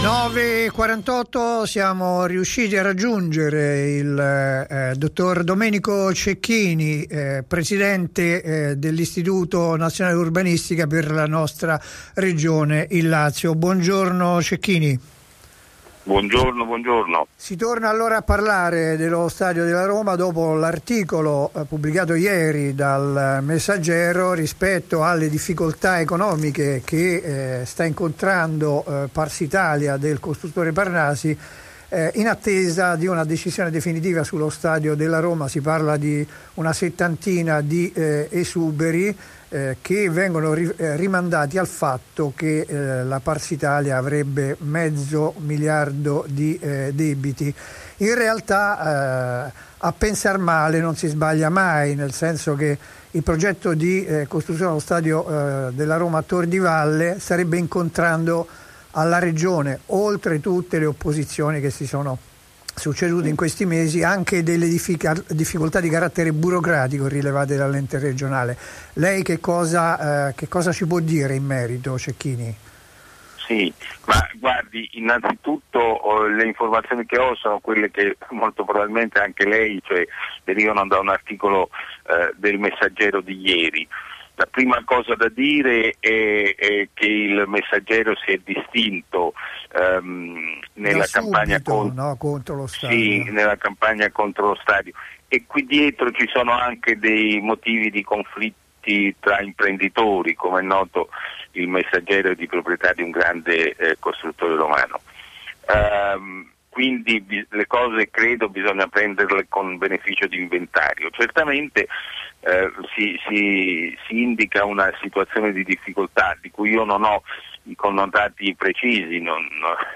intervistato da Radio popolare sul progetto del nuovo stadio della Roma a Tor di Valle quando la realizzazione del complesso sembra complicarsi. Ribadite le ragioni del no dell'Inu.